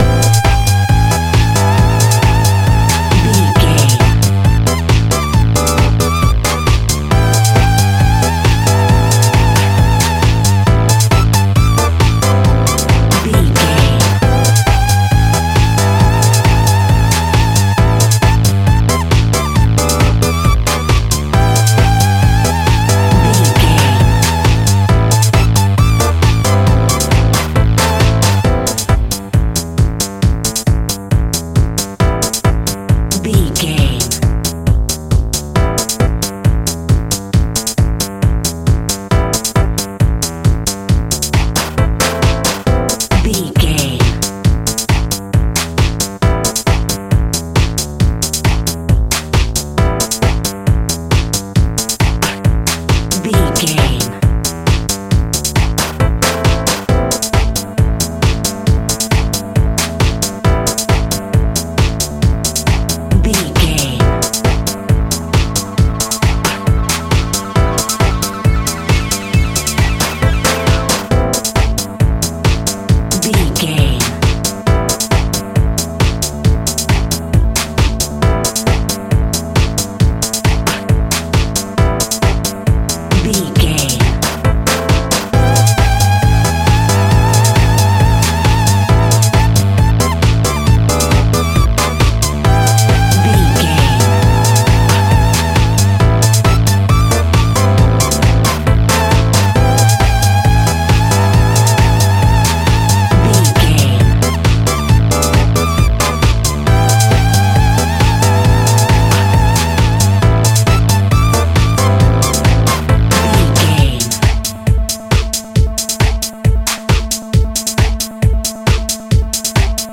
Aeolian/Minor
groovy
driving
energetic
electric piano
synthesiser
drums
upbeat
synth lead
synth bass